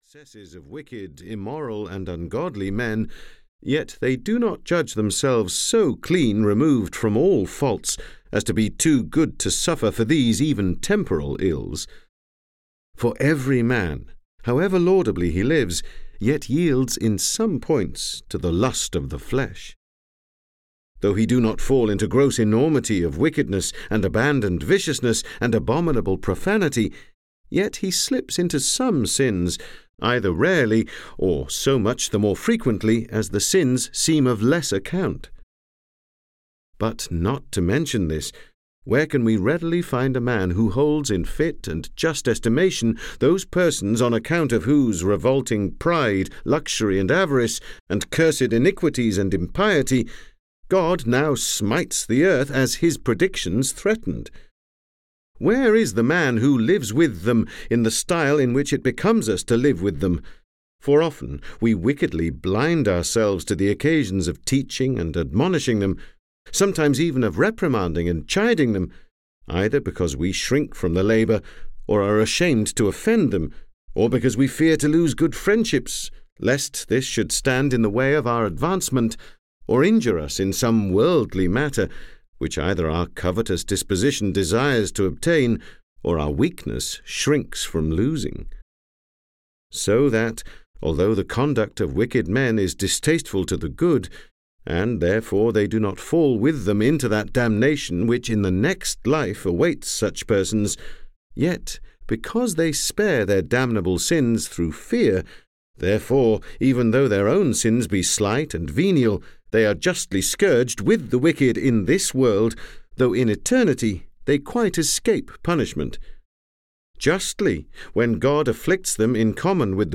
City of God (EN) audiokniha
Ukázka z knihy